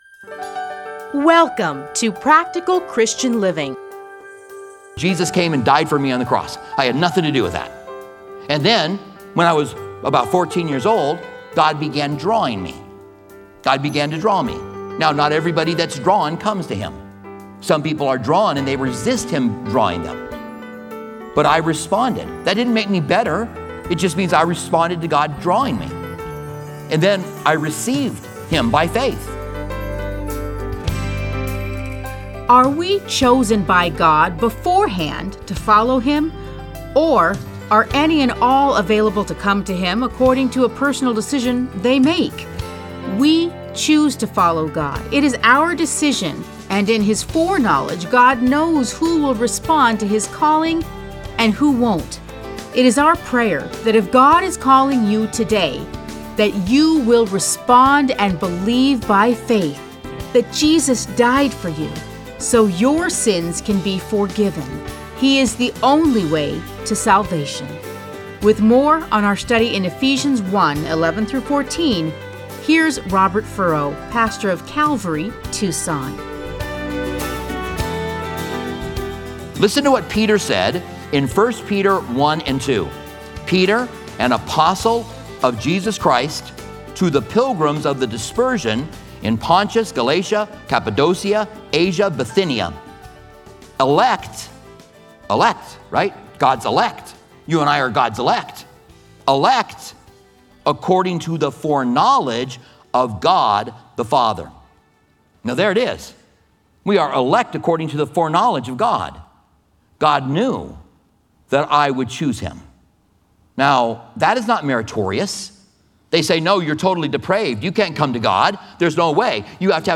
Listen to a teaching from Ephesians 1:11-14.